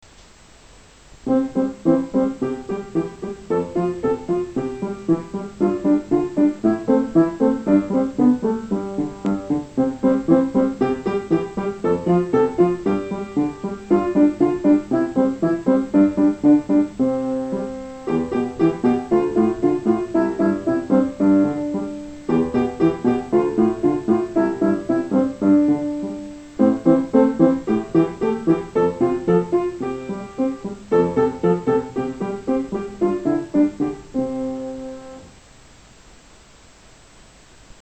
Eighth note rhythm
corxera_oques.mp3